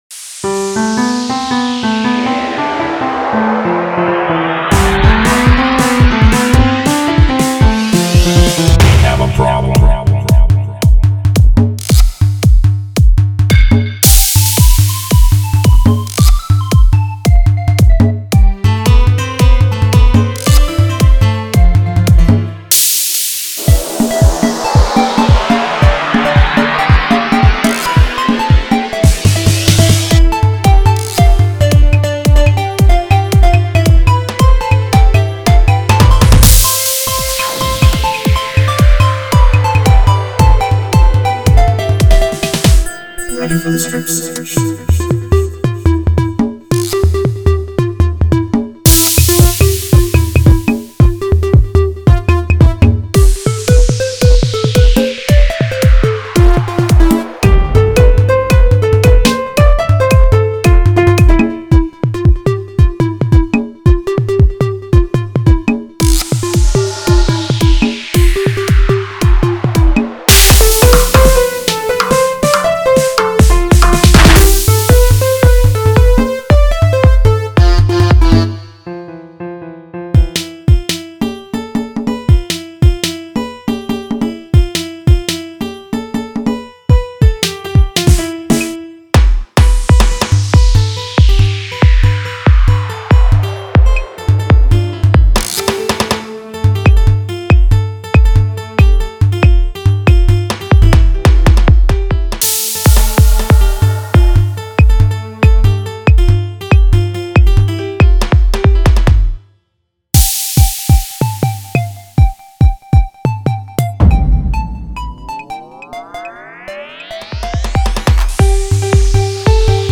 آهنگ شاد بی کلام